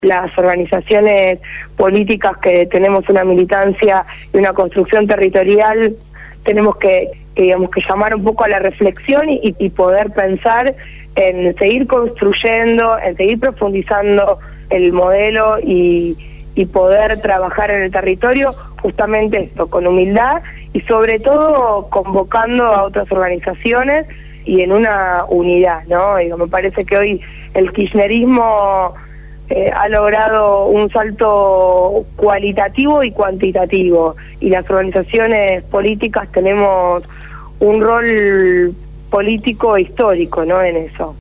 Victoria Colombo, referente de la agrupación La Cámpora y Comunera electa en la Comuna 4 por el Frente Para la Victoria habló en el programa Punto de Partida de Radio Gráfica FM 89.3 con motivo del Festival por el Día del Niño que se realizará a partir de las 14 horas en Iriarte y Vélez Sarfield, en el Barrio de Barracas de la Ciudad de Buenos Aires.